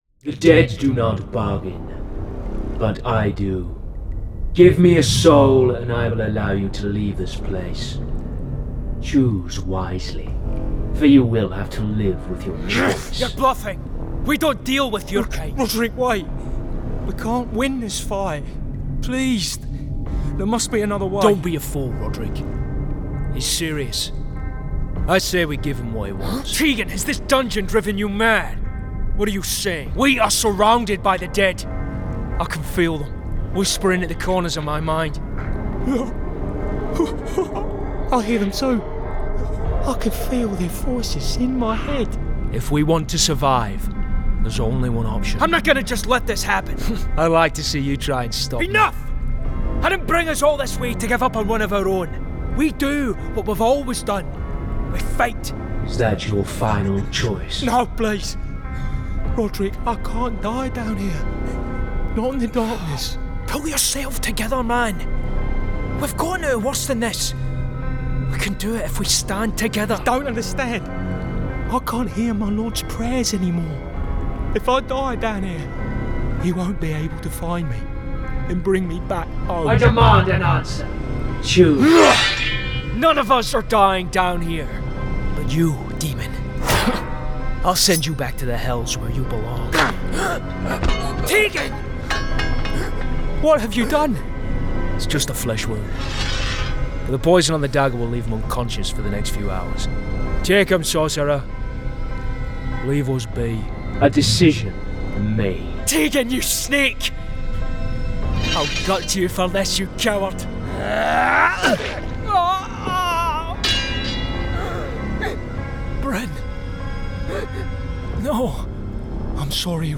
Video Game Showreel
Known for his professionalism and versatility, he delivers a wide range of UK and international accents from his broadcast-quality home studio in Glasgow.
Male
Scottish
Friendly
Warm
Confident